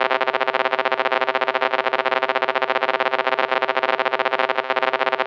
radioBuzzy_1b.wav